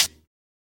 Closed Hats
HI HAT RUGGED.wav